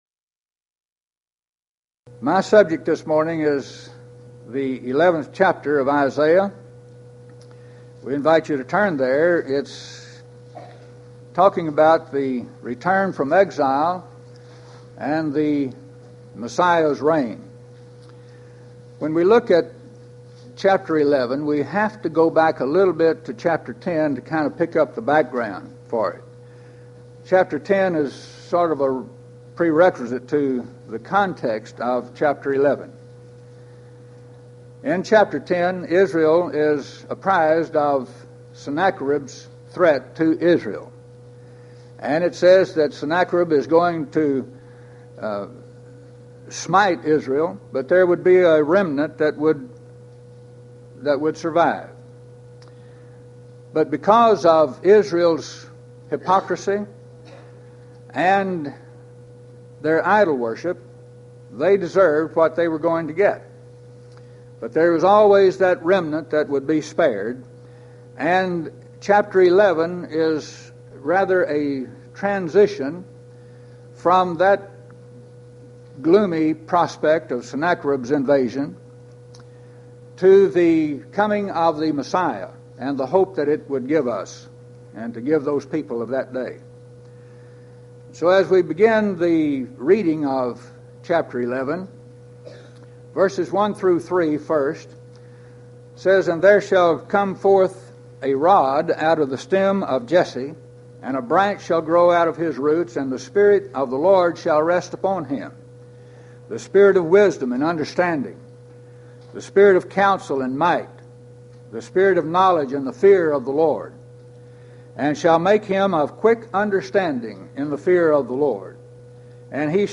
Event: 1995 HCB Lectures Theme/Title: The Book Of Isaiah - Part I
lecture